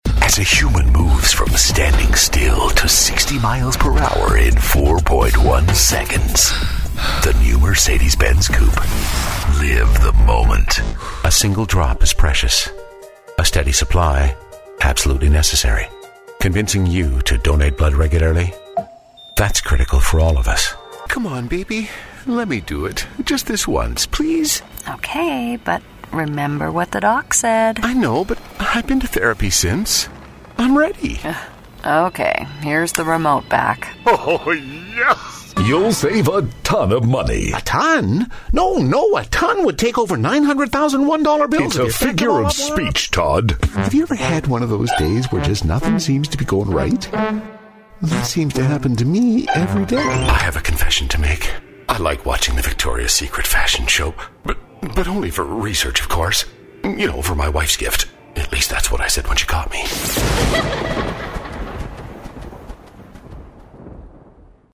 Commercials - Education - Corporate